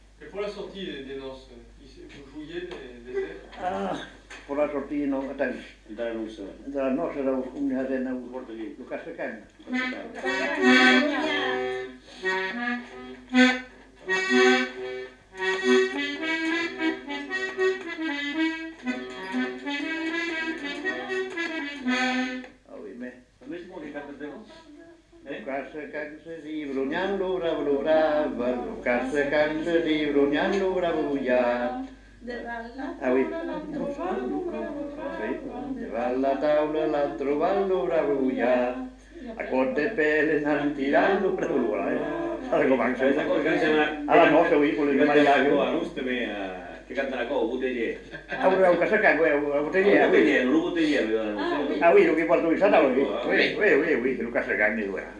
Aire culturelle : Petites-Landes
Genre : chant
Type de voix : voix d'homme
Production du son : chanté
Instrument de musique : accordéon diatonique
Notes consultables : Plusieurs voix d'homme.